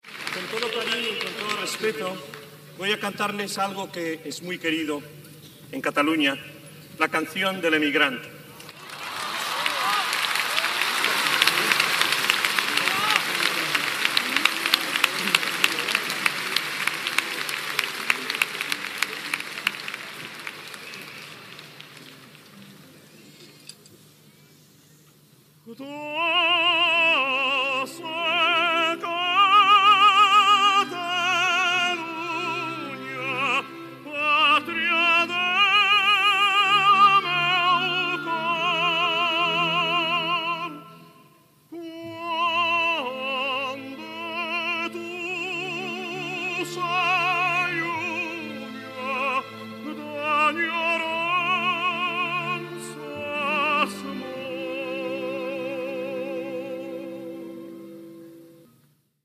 Transmissió del Concert Extraordinari Pro-Damnificats de Mèxic, fet des del Gran Teatre del Liceu. El cantant Plácido Domingo canta "L'emigrant"
Musical